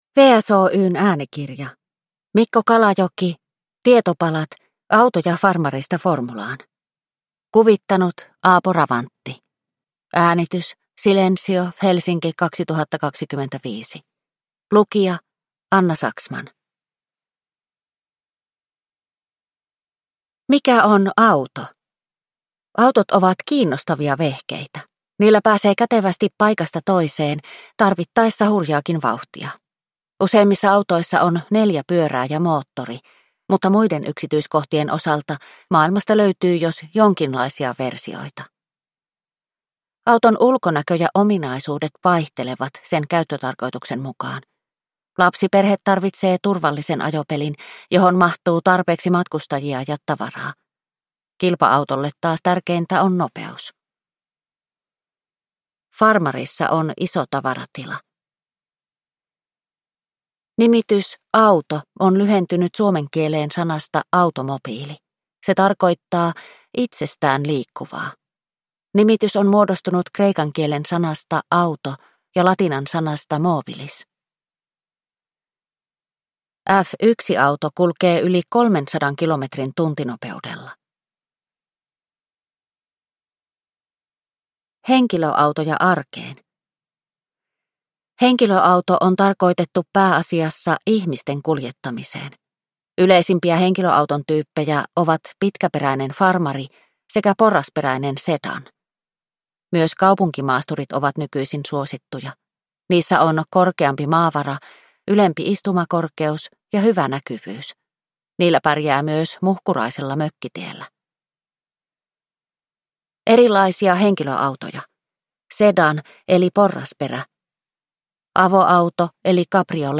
Tietopalat: Autoja farmarista formulaan – Ljudbok